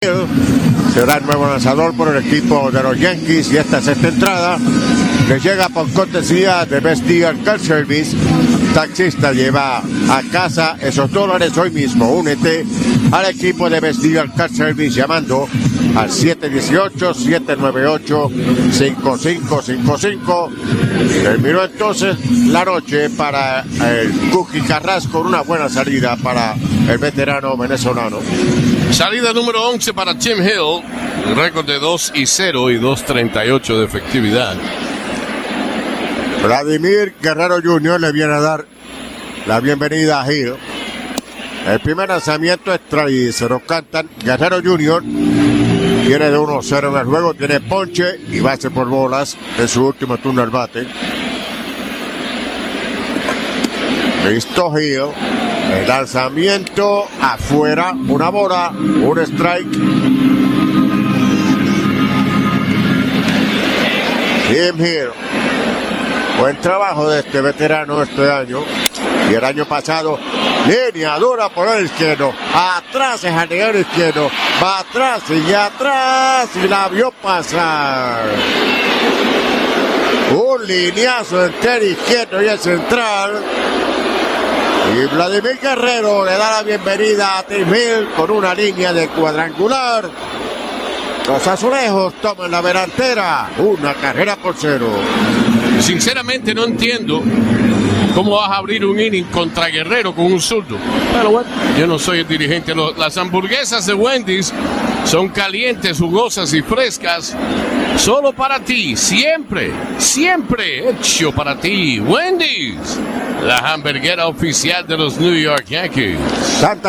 V.Guerrero-HR-162-Radio-NYY.mp3